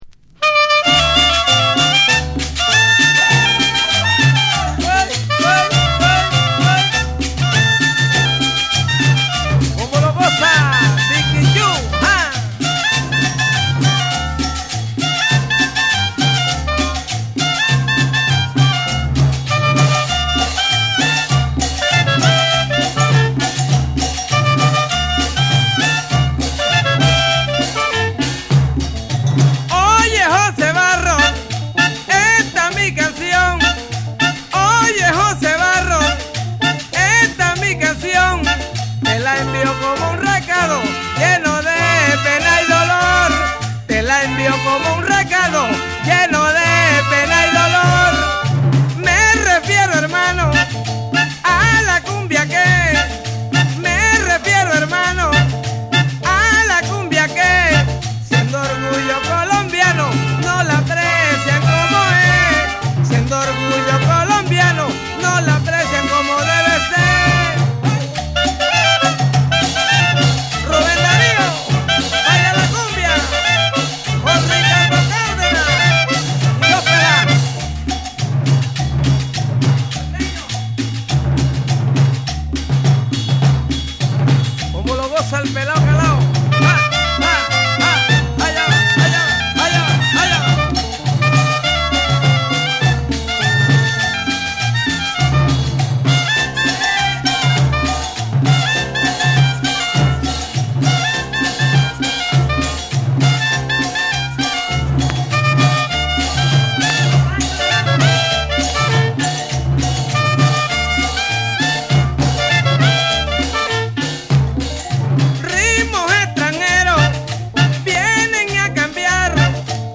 陽気でカラフルなリズム、伸びやかなホーンやギターが絡み合うトロピカル・サウンドが全編を彩る一枚。
WORLD